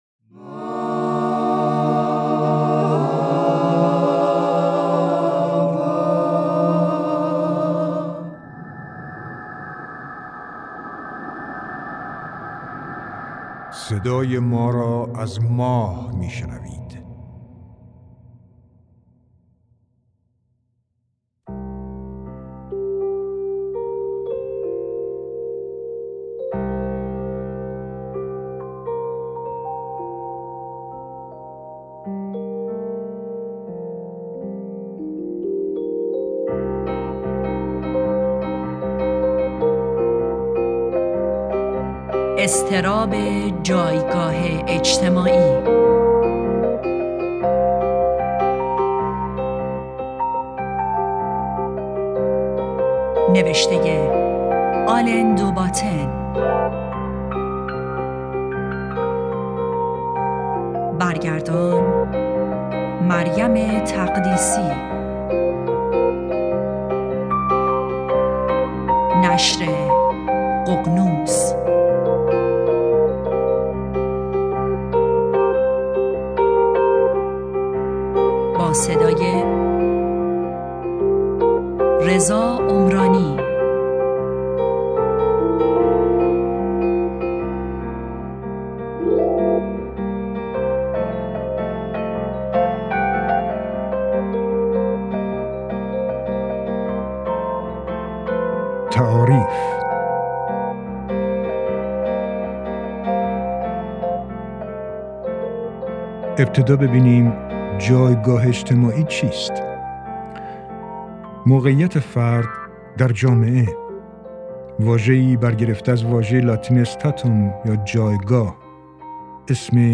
کتاب صوتی اضطراب جایگاه اجتماعی (آلن دوباتن) (1)